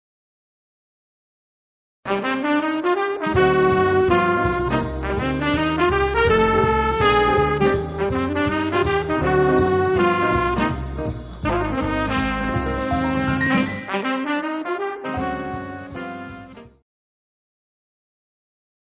I kept it to medium swing, one quarter note equals 130.